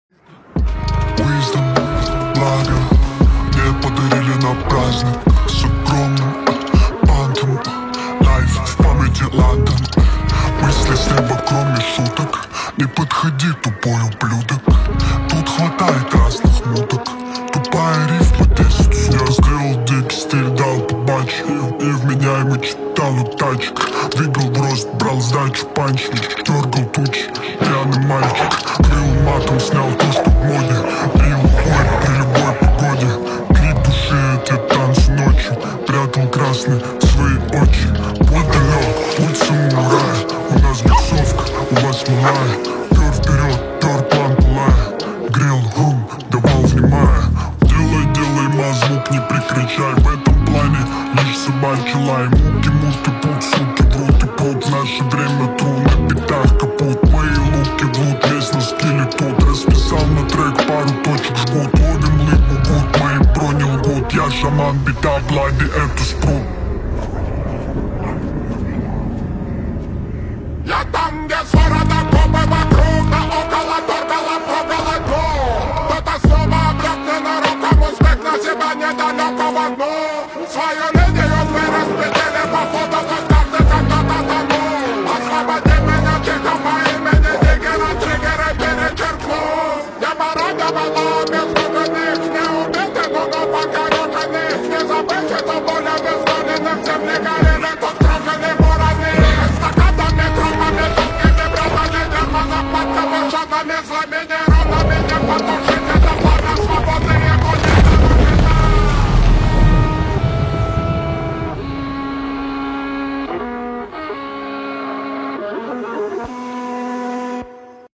Рэп, Хип-хоп